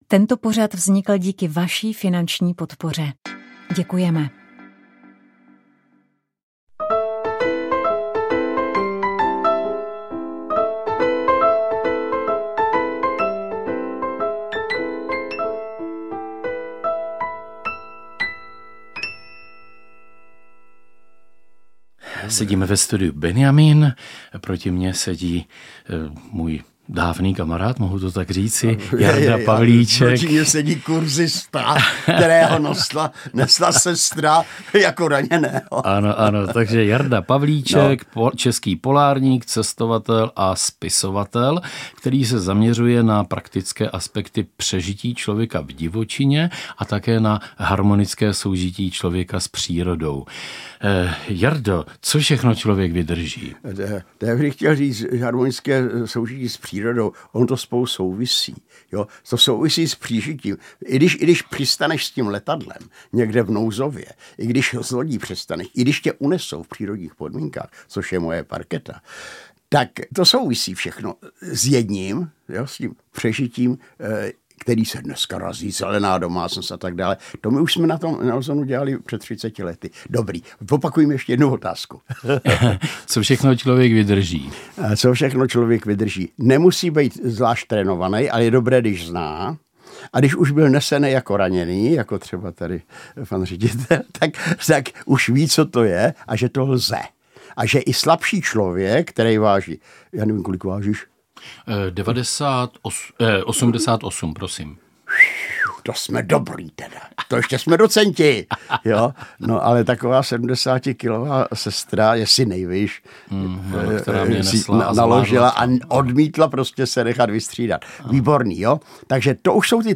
Péči o těhotné ženy s preeklampsií se bude na Proglasu věnovat rozhovor